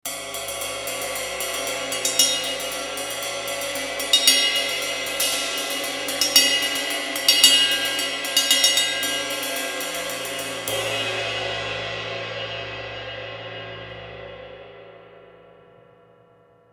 K ride 22